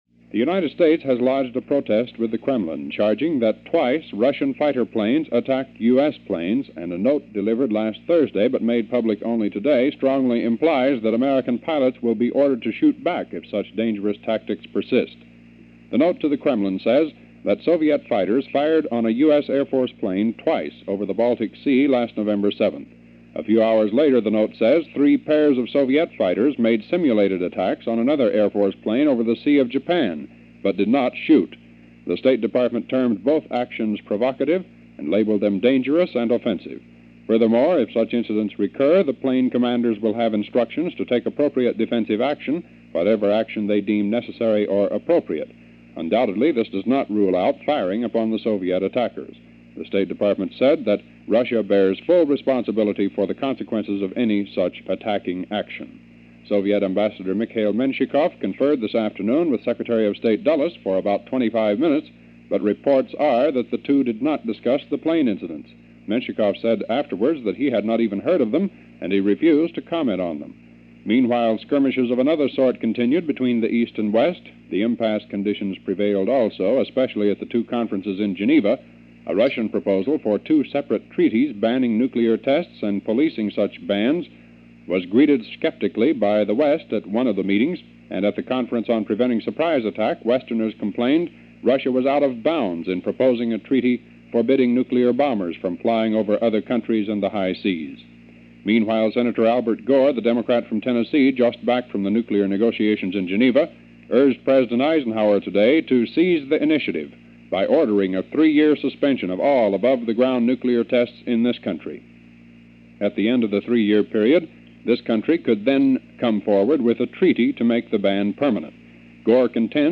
News and the goings on in the world for this day in 1958 as presented by WJR-Detroit.